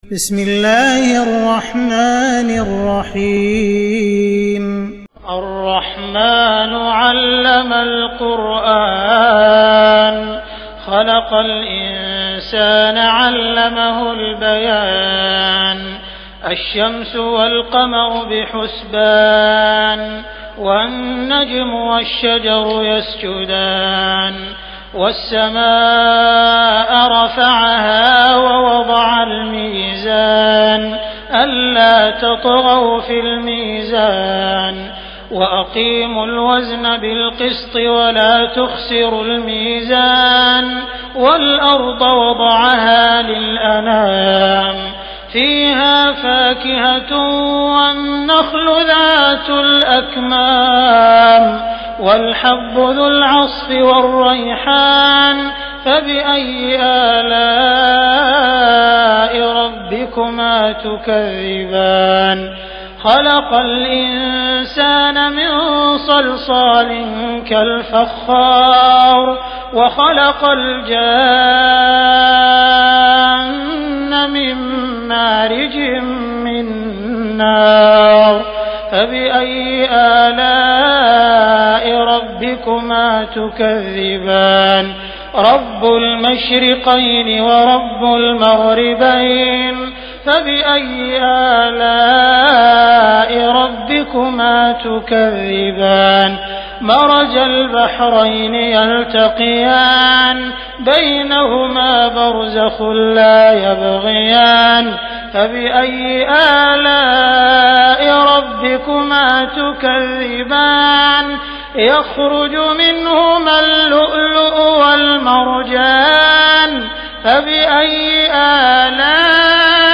تراويح ليلة 26 رمضان 1418هـ من سور الرحمن الواقعة و الحديد Taraweeh 26 st night Ramadan 1418H from Surah Ar-Rahmaan and Al-Waaqia and Al-Hadid > تراويح الحرم المكي عام 1418 🕋 > التراويح - تلاوات الحرمين